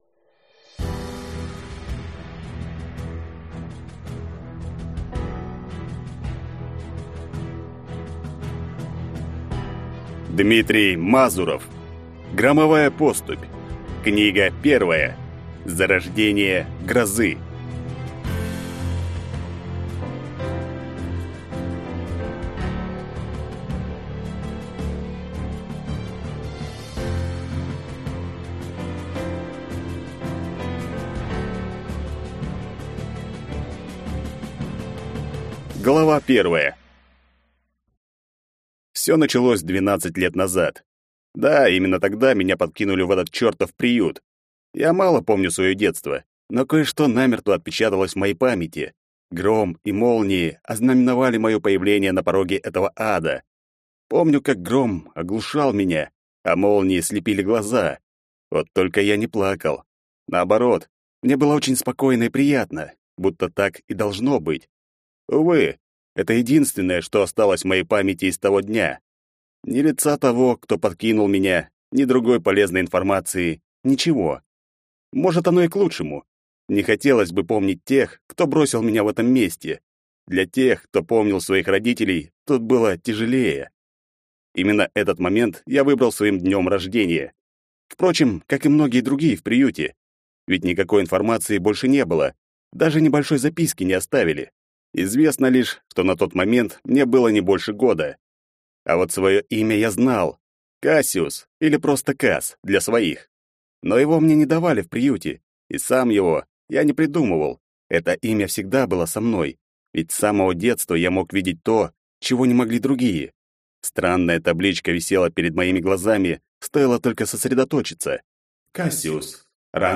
Аудиокнига Громовая поступь 1. Зарождение грозы | Библиотека аудиокниг
Прослушать и бесплатно скачать фрагмент аудиокниги